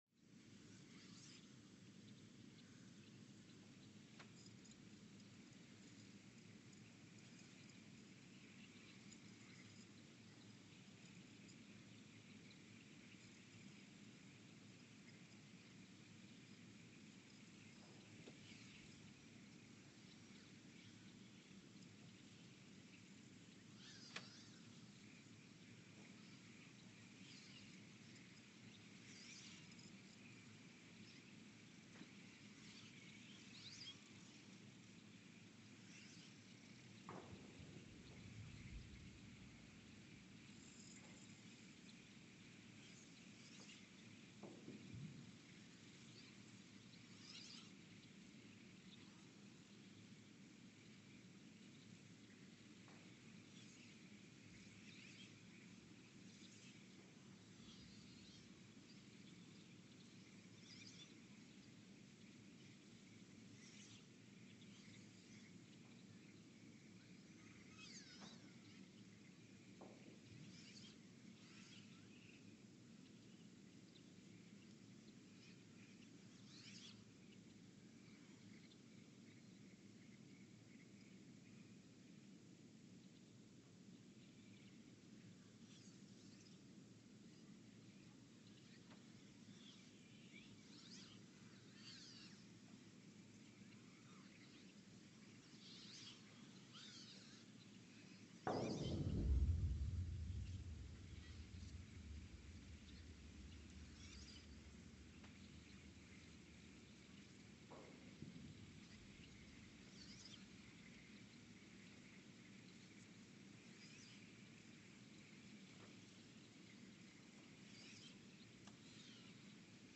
The Earthsound Project is an ongoing audio and conceptual experiment to bring the deep seismic and atmospheric sounds of the planet into conscious awareness.
Sensor : STS-1V/VBB Recorder : Quanterra QX80 @ 20 Hz
Speedup : ×900 (transposed up about 10 octaves) Loop duration (real) : 168 hours Loop duration (audio) : 11:12 (stereo)